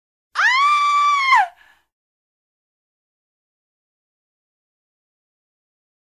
女人惊恐尖叫音效免费音频素材下载